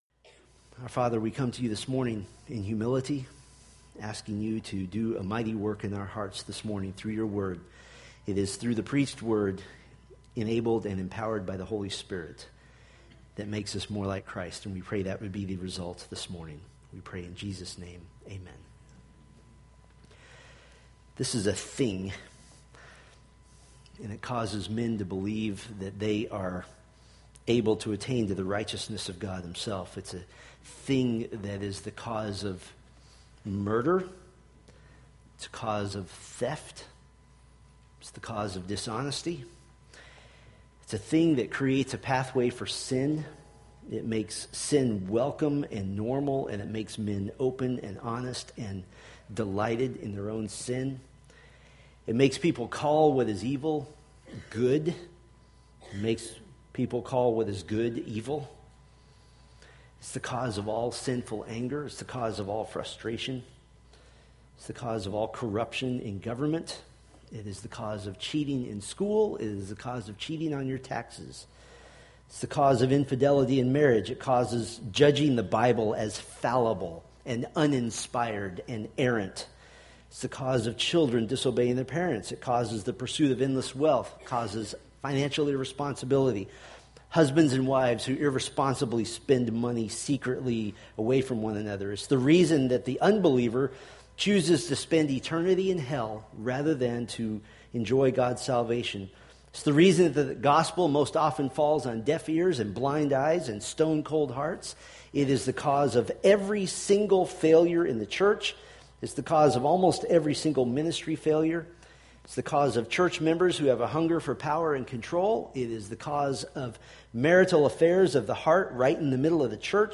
Preached October 25, 2015 from Isaiah 2:6-4:1